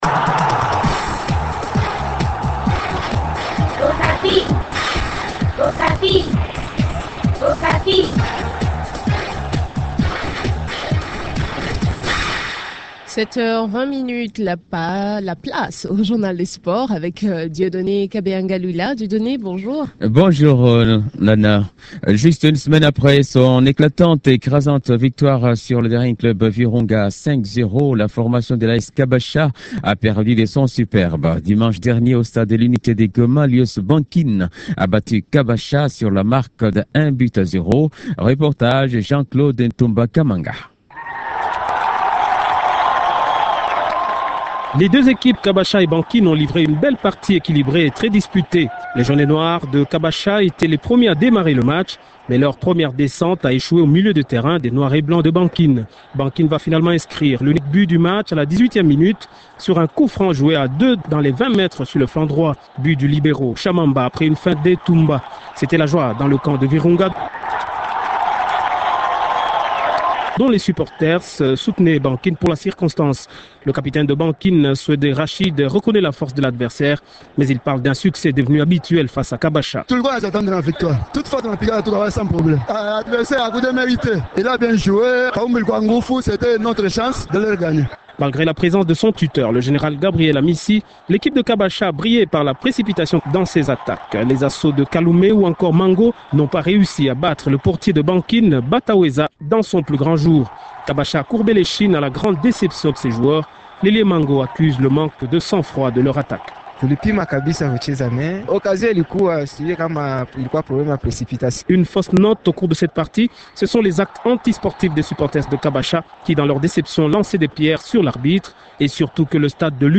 Journal des sports